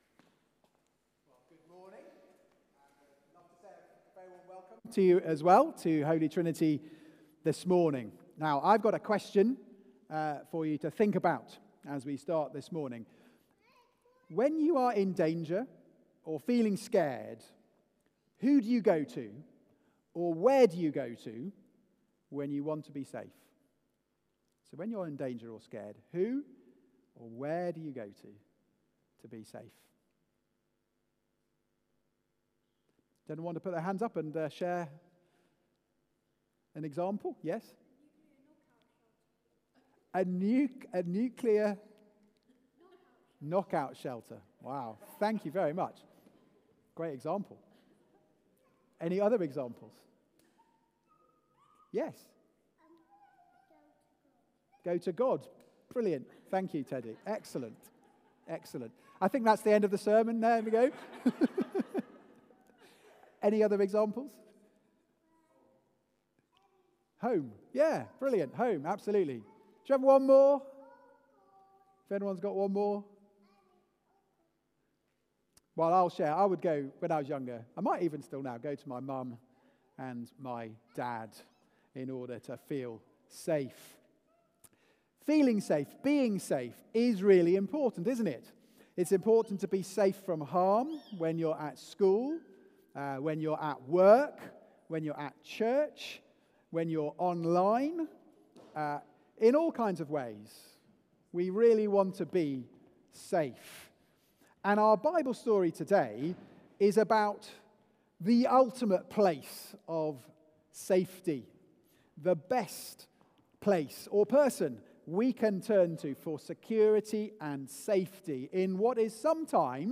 Media for Service (10.45) on Sun 19th Jan 2025